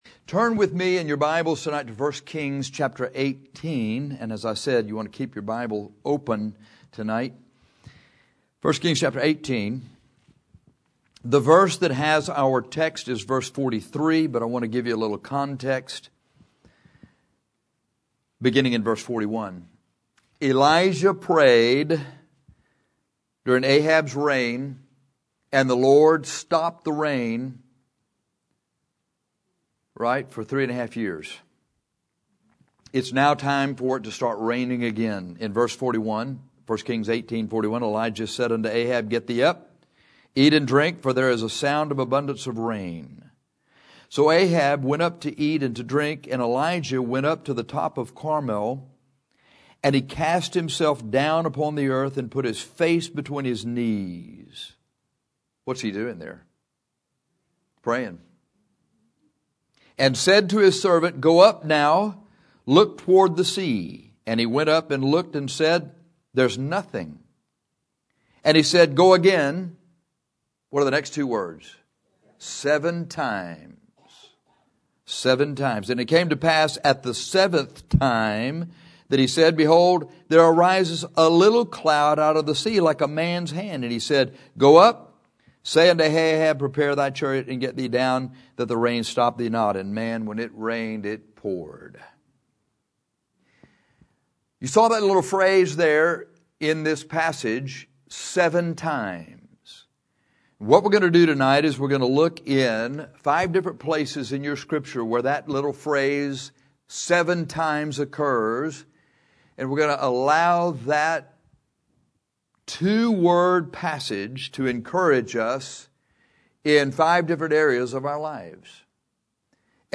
This sermon is on some verses in the Bible where we find the phrase “seven times”.